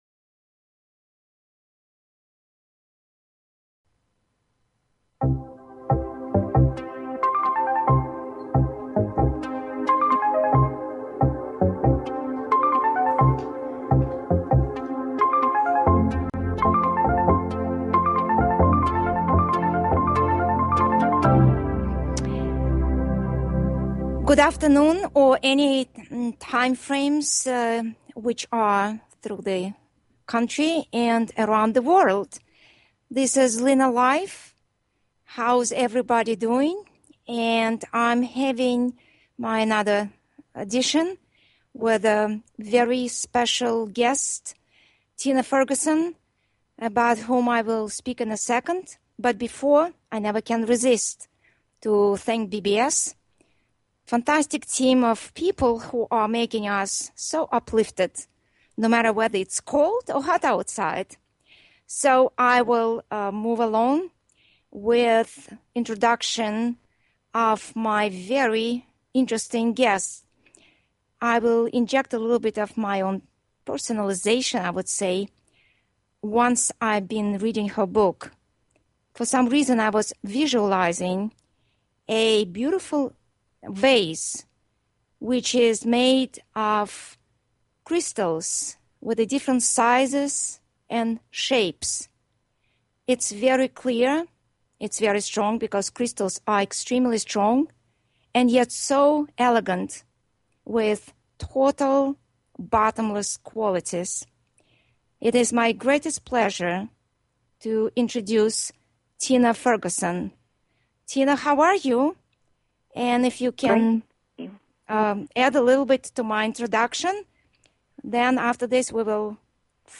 Piano Music
Talk Show